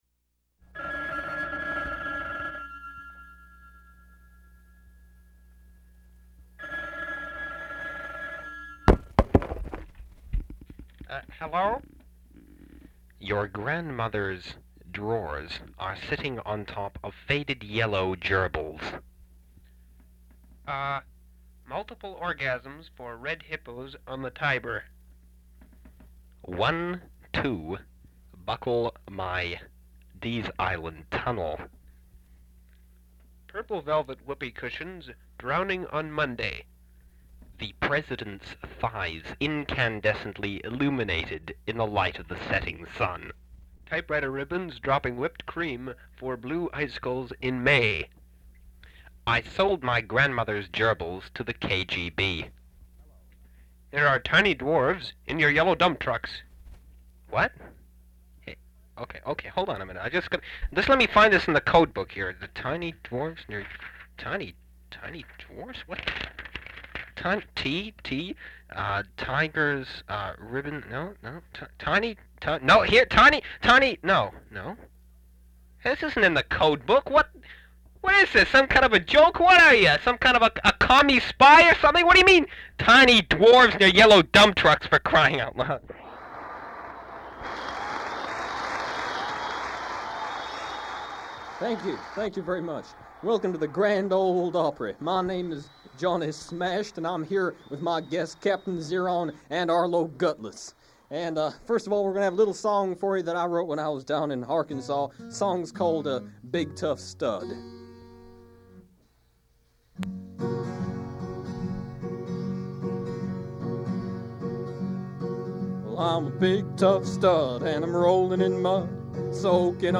Recording of an episode of the UBC Radio Society's Tiny Dwarves comedy program.